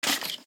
Minecraft Version Minecraft Version snapshot Latest Release | Latest Snapshot snapshot / assets / minecraft / sounds / mob / stray / step1.ogg Compare With Compare With Latest Release | Latest Snapshot
step1.ogg